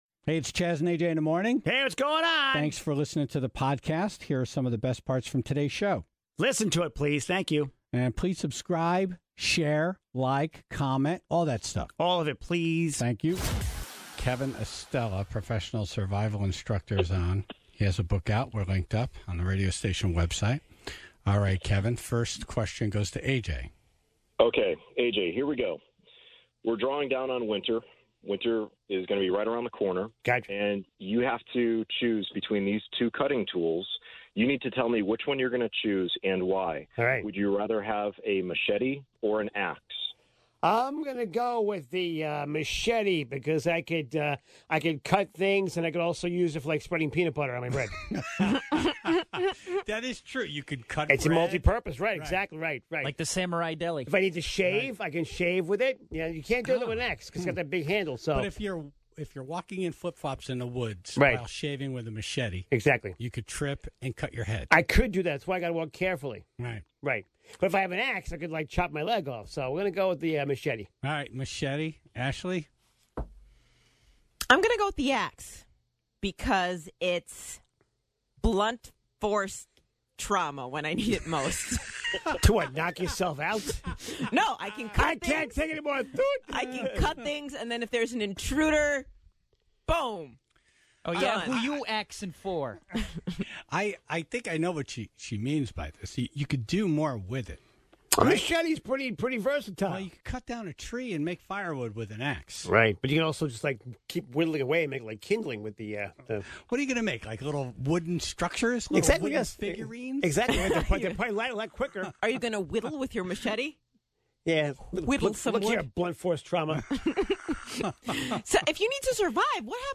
However, thanks to the magic of auto-tuning, the song is bearable.